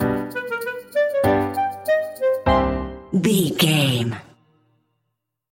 Uplifting
Aeolian/Minor
flute
oboe
strings
orchestra
cello
double bass
percussion
silly
circus
goofy
cheerful
perky
Light hearted
quirky